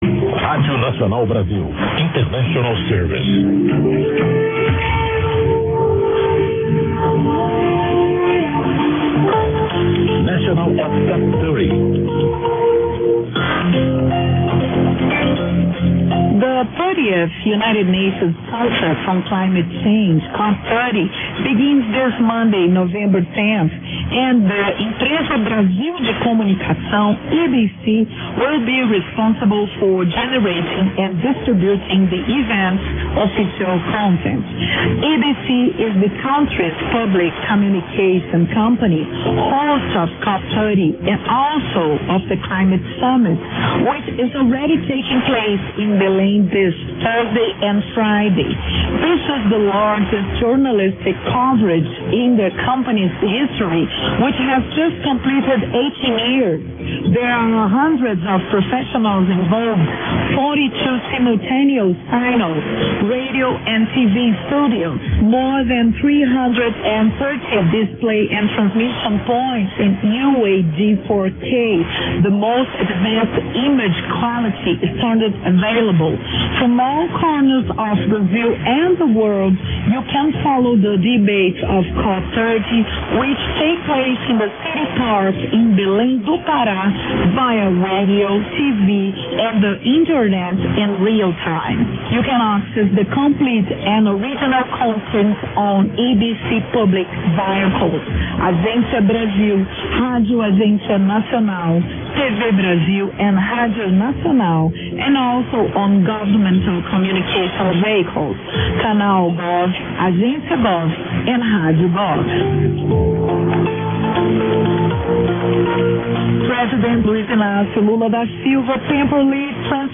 Audio Station ID and News in English